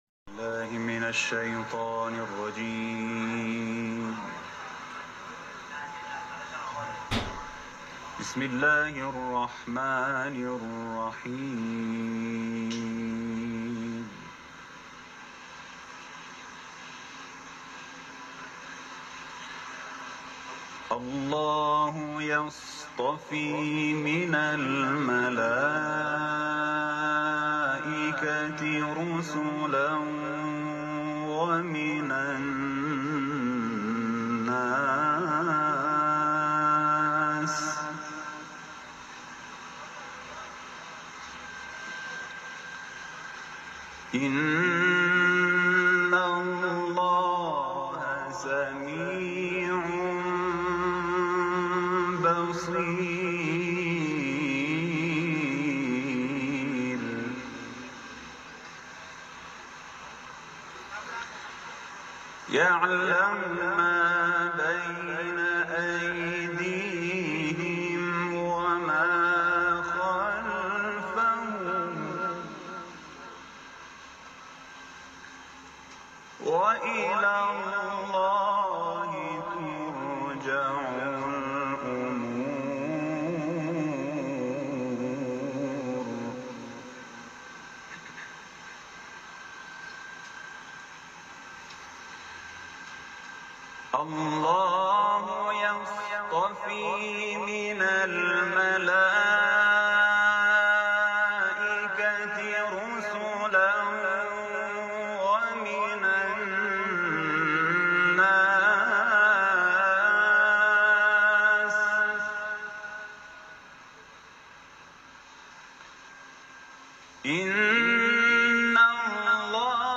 تلاوت
سوره حج ، حرم مطهر رضوی